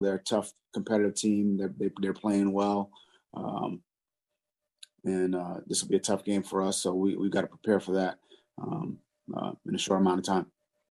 Dolphins’ head coach Brian Flores addresses the Jaguars as tough matchup for Miami.